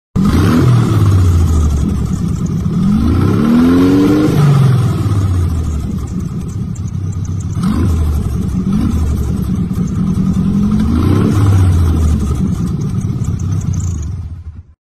Unsere beiden Porsche 356 Speedster sind daher zu 100 % zuverlässig und bieten zudem einen fantastischen Porsche-Sound, da beide Klassiker mit einem originalen Porsche-Auspuff ausgestattet sind.
Porsche-356-Speedster-motor-geluid-kevermobiel.mp3